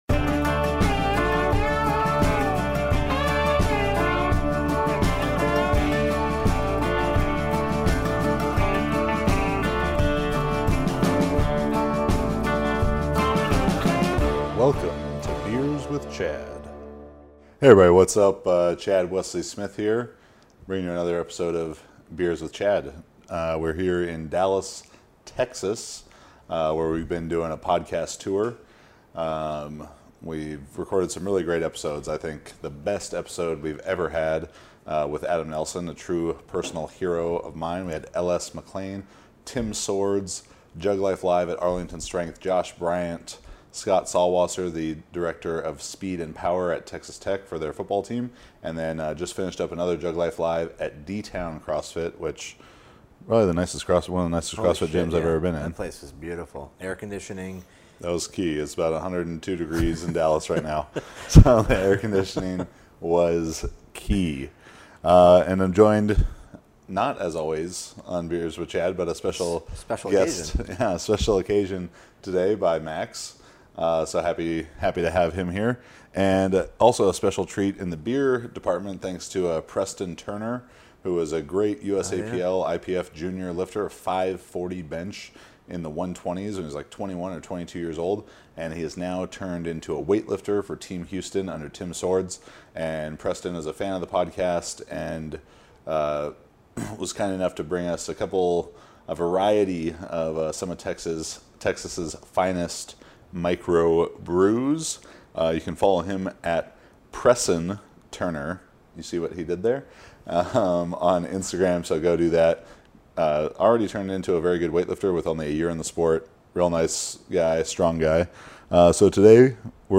come to you from the great state of Texas, drinking a variety of the Lone Star State's finest beers, and discussing the Mt. Rushmore of Weightlifting.